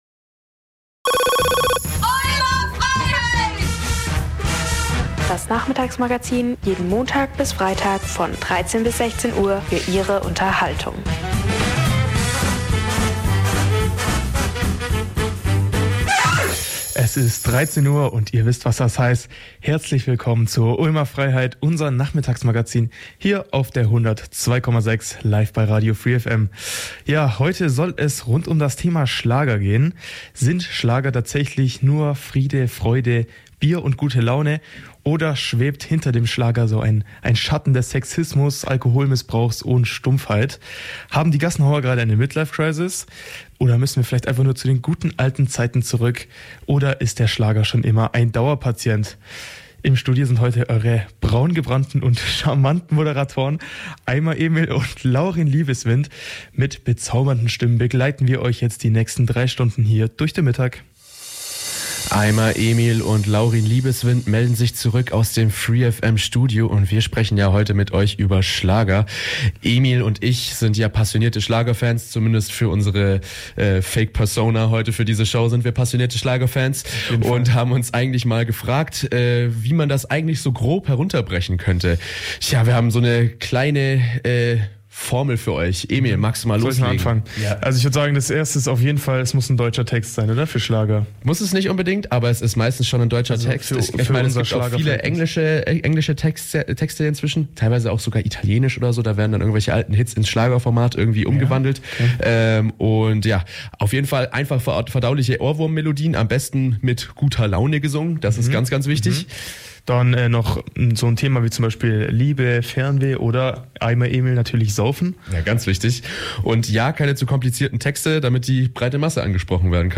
Heute zu Gast ist die Autorin Olivia Wenzel.